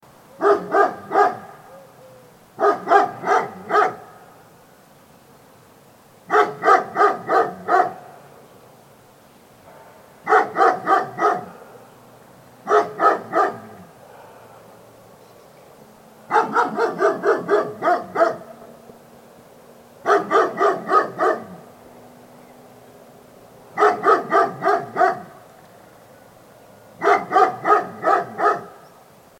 دانلود آهنگ سگ از افکت صوتی انسان و موجودات زنده
جلوه های صوتی
دانلود صدای سگ از ساعد نیوز با لینک مستقیم و کیفیت بالا